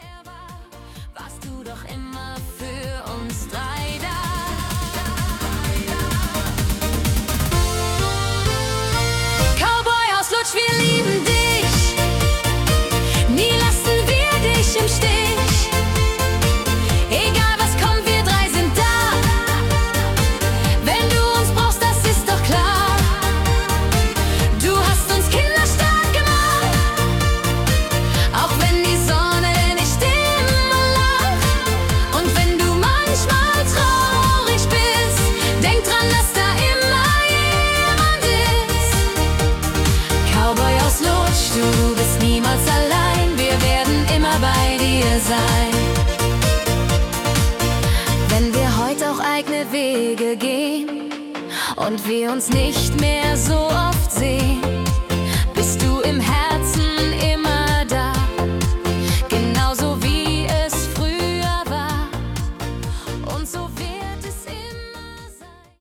Schlager